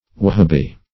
Search Result for " wahabee" : The Collaborative International Dictionary of English v.0.48: Wahabee \Wa*ha"bee\, n. [Ar. wah[=a]bi.]